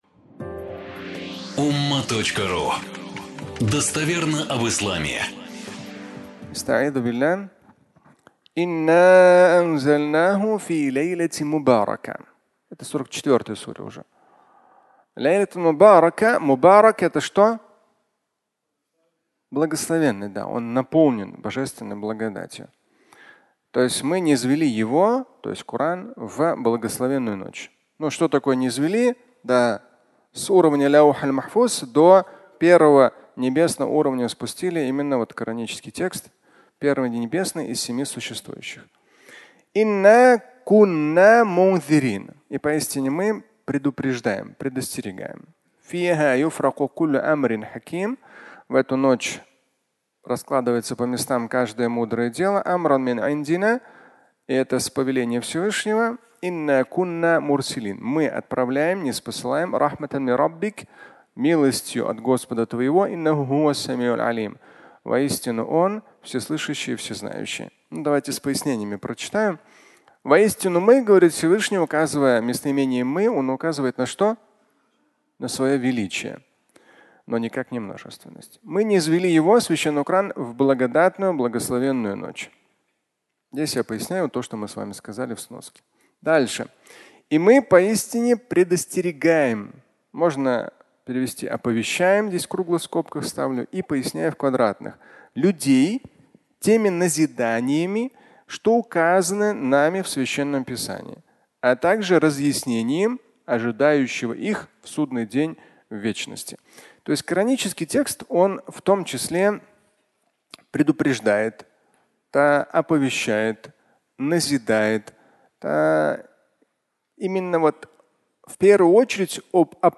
Ночь могущества 2 (аудиолекция)